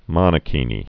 (mŏnə-kēnē)